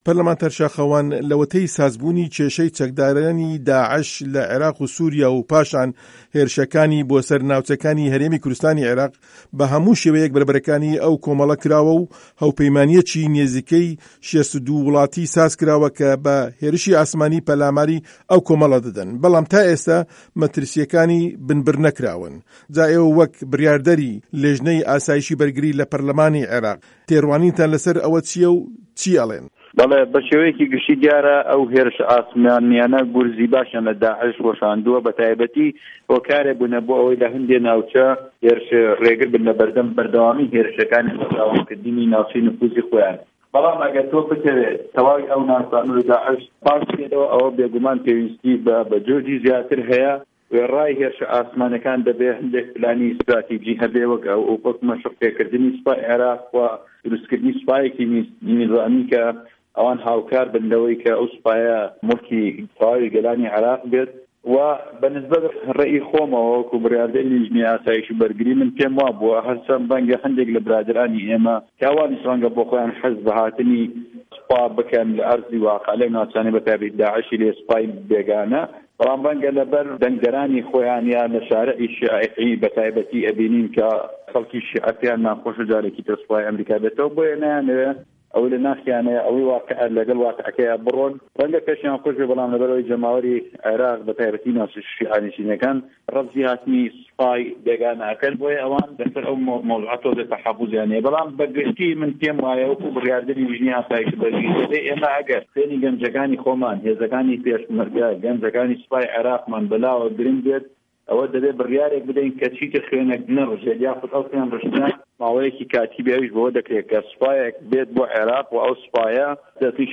وتووێژی شاخه‌وان عه‌بدوڵڵا